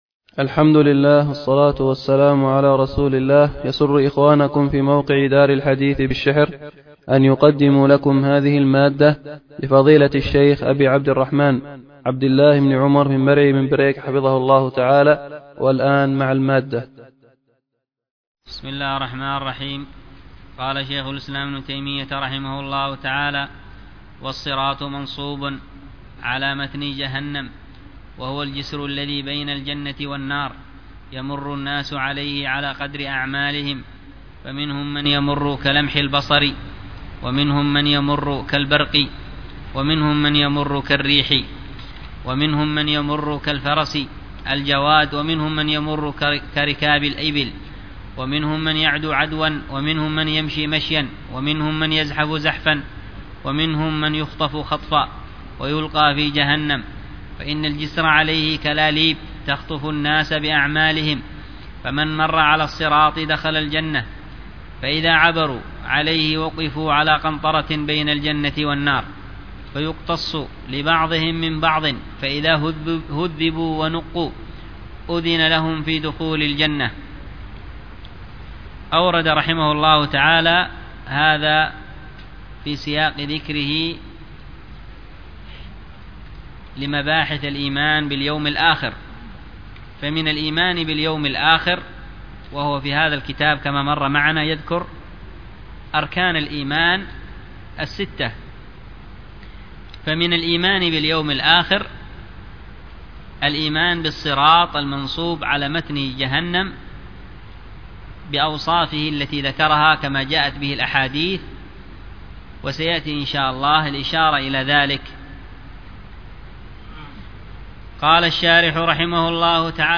الدرس في شرح العقيدة الواسطية 69، ألقاها